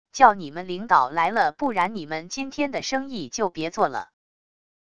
叫你们领导来了不然你们今天的生意就别做了wav音频生成系统WAV Audio Player